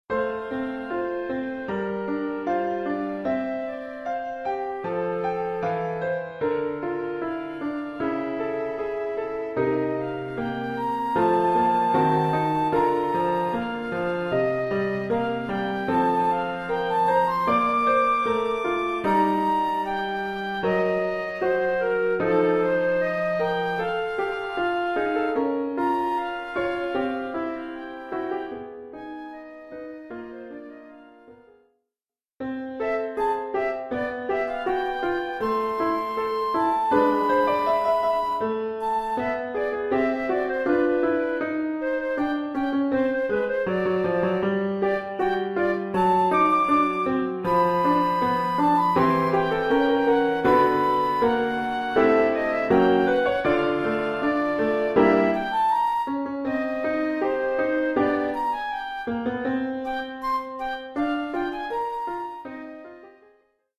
1 titre, ¨flûte et piano : conducteur et partie de flûte ut
Oeuvre pour flûte et piano.
Niveau : préparatoire.